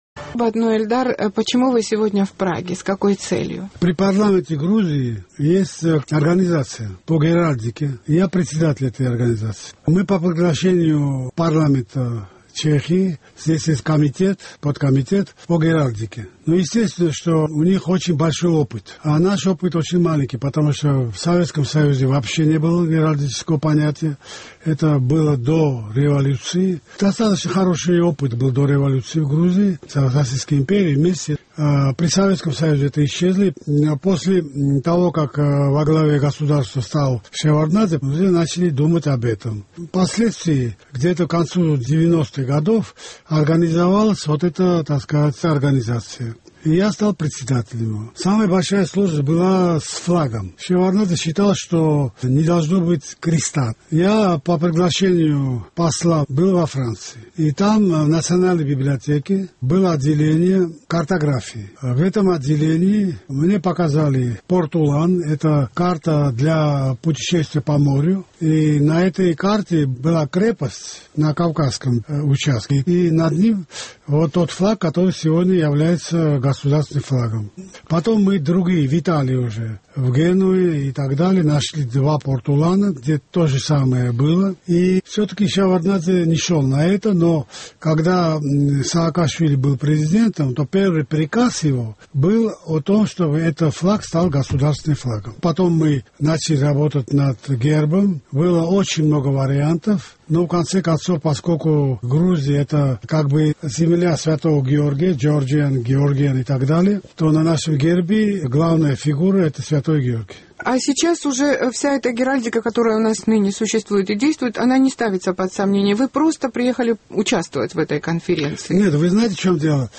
Сегодня у нас в гостях известный грузинский режиссер Эльдар Шенгелая.